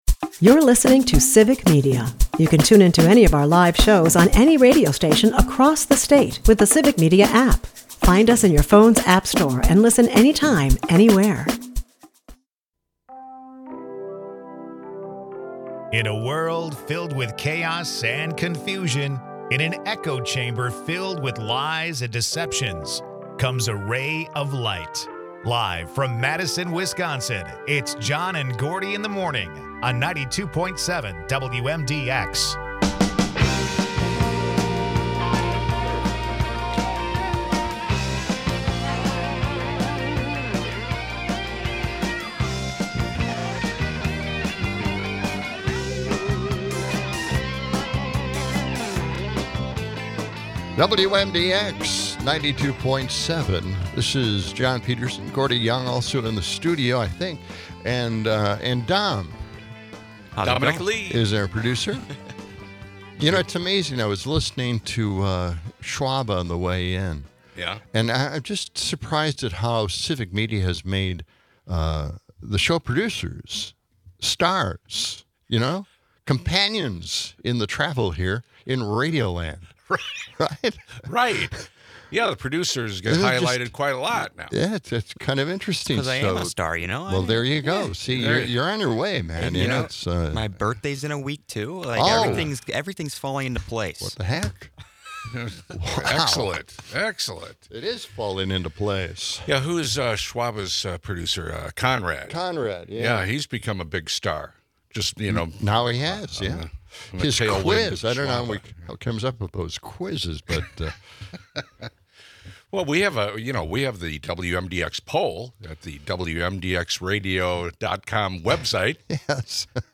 Discussions shift to national issues, highlighting the alarming escalation of military presence in LA. Callers voice concerns over the political climate.